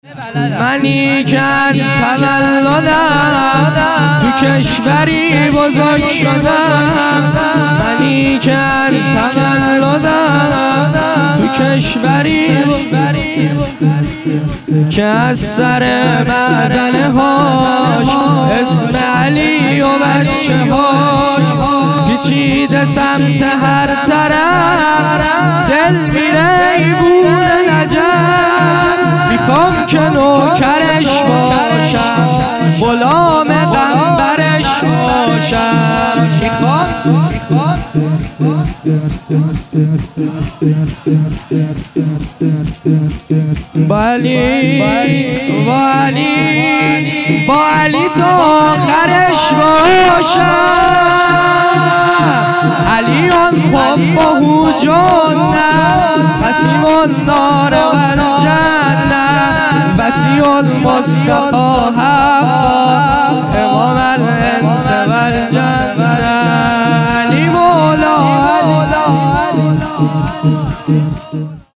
صوت جلسه عید مبعث
📼 صوت سرود منی که از تولدم